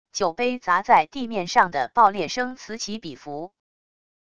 酒杯砸在地面上的爆裂声此起彼伏wav音频